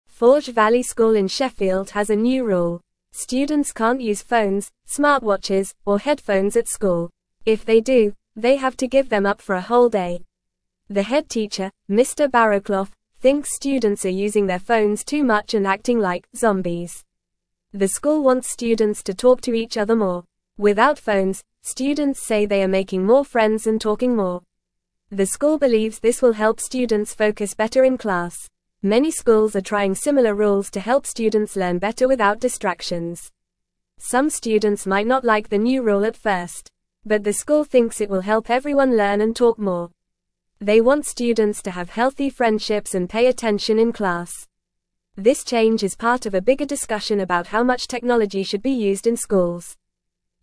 Fast
English-Newsroom-Lower-Intermediate-FAST-Reading-No-Phones-Allowed-at-Forge-Valley-School-in-Sheffield.mp3